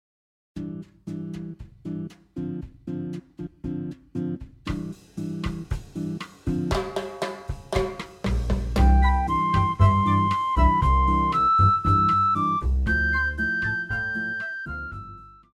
高音直笛
樂團
聖誕歌曲,聖歌,教會音樂,古典音樂
獨奏與伴奏
有主奏
有節拍器